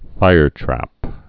(fīrtrăp)